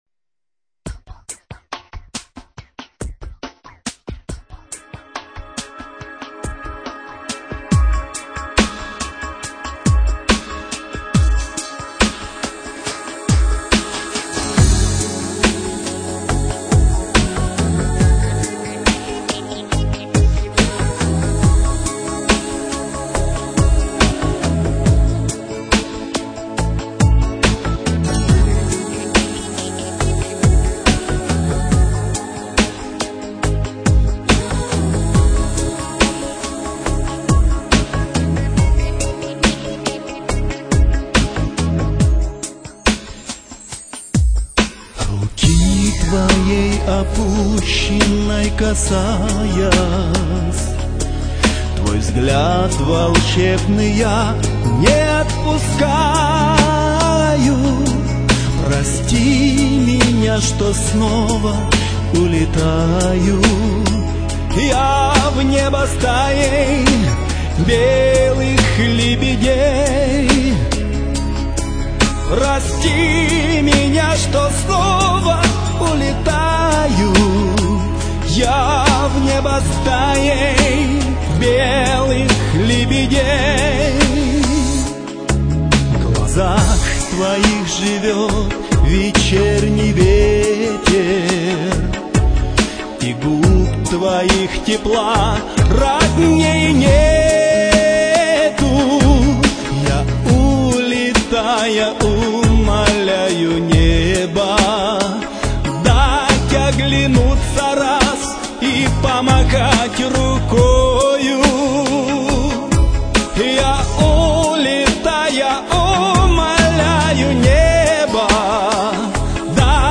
романс